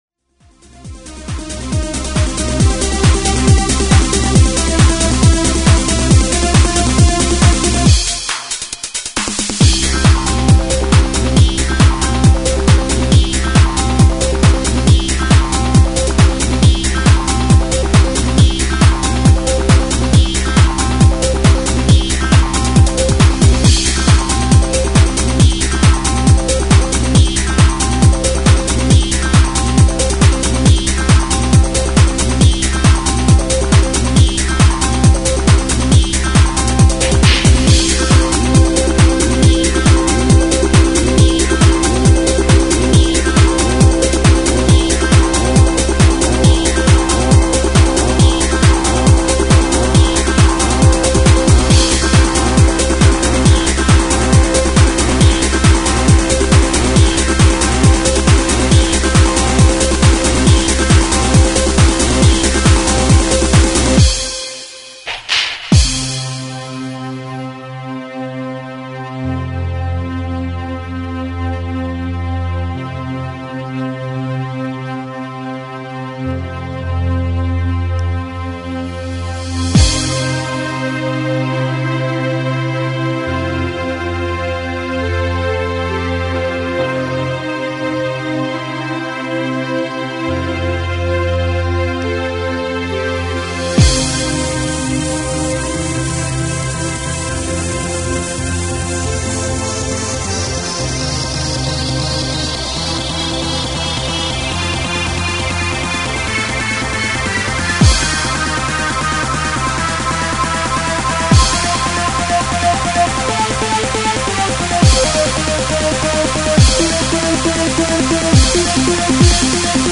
Trance
darkly tinged yet ravey lead riff melody
relentless bubbling grooves and psy arps!